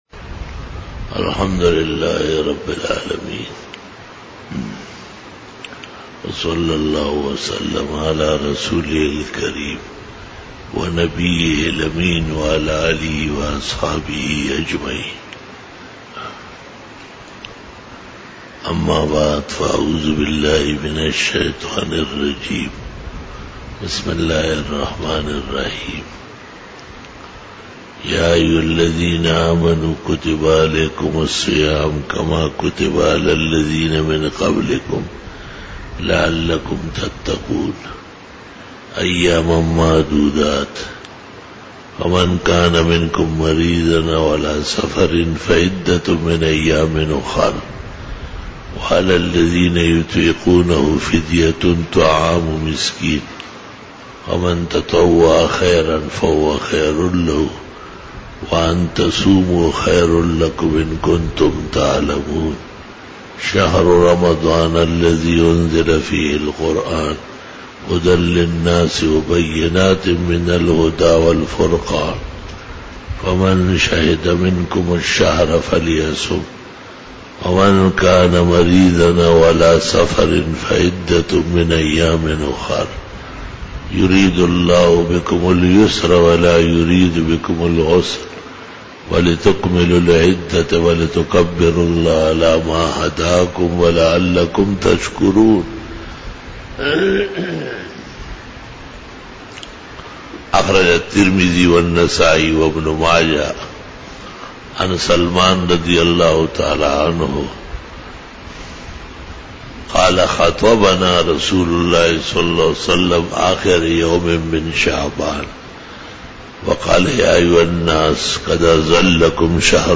20 BAYAN E JUMA TUL MUBARAK (18 May 2018) (02 Ramadan 1439H)
Khitab-e-Jummah 2018